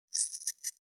506桂むき,大根の桂むきの音切る,包丁,厨房,台所,野菜切る,咀嚼音,ナイフ,調理音,
効果音厨房/台所/レストラン/kitchen食材